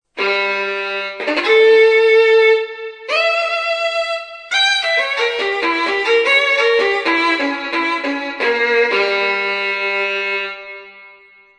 I file MIDI sono le take originali così come sono state registrate con il guitar synth: non c'è stato nessun editing "a posteriori".
Violin Demo (MIDI) (
mp3) - registrato con pitch bend=12
GuitarSynthViolinDemo.mp3